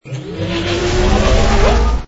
shield_rebuilt.wav